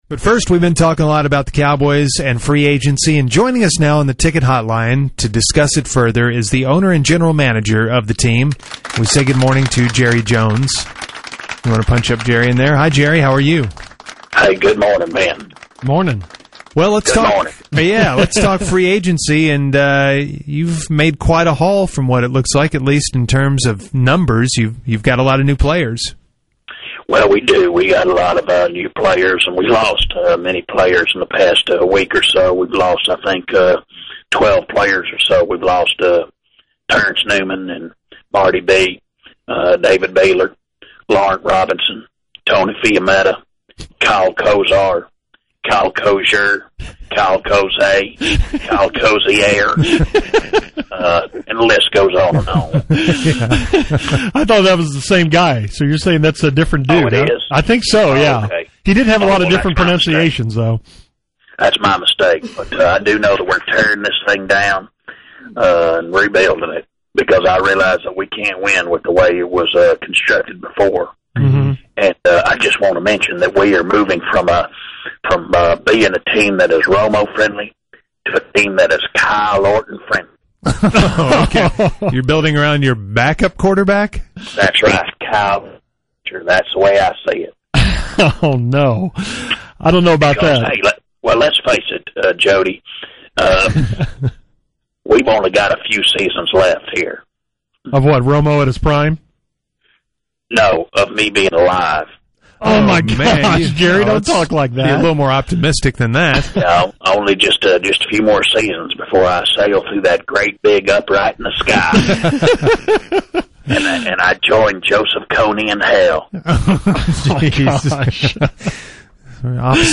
The Musers had Fake Jerry on Monday morning to discuss the Cowboys free-agency.